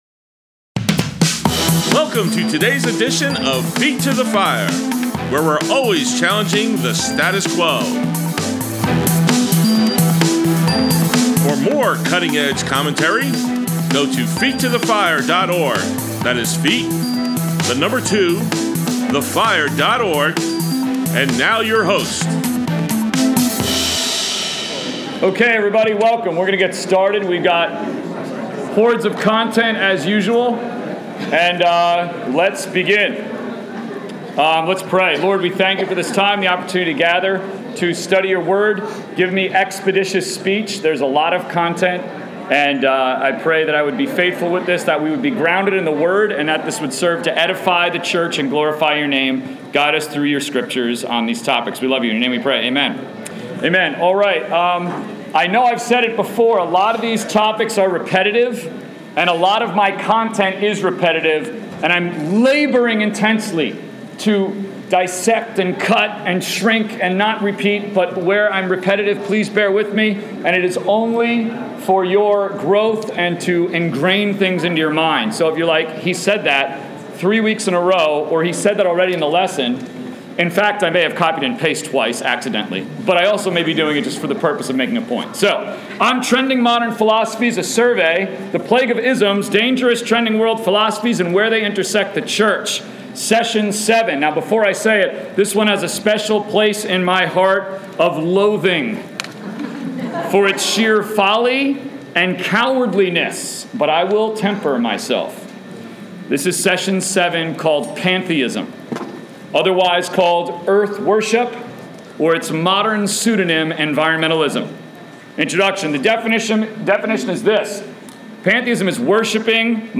Adult Sunday School